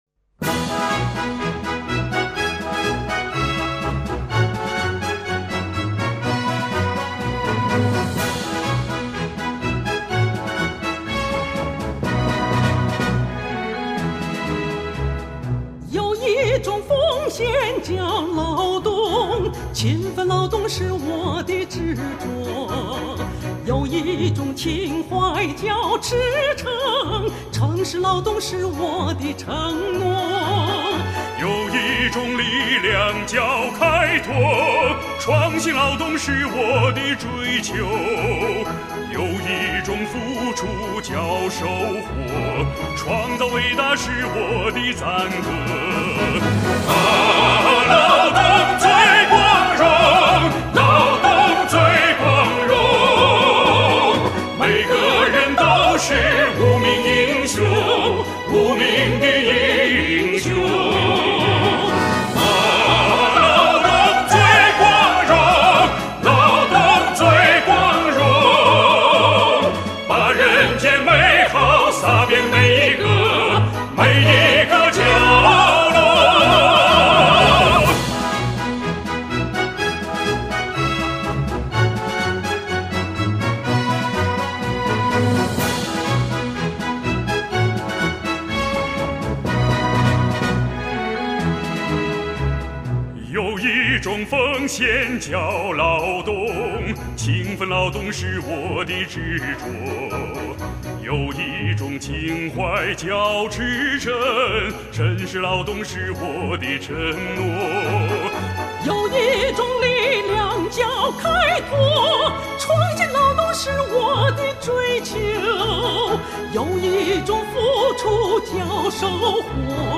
浑厚的嗓音，
热情洋溢的歌声，完美演绎听众喜爱的艺术歌曲。
录制来自各大晚会演出，歌曲脍炙人口，制作精良，极具收藏价值。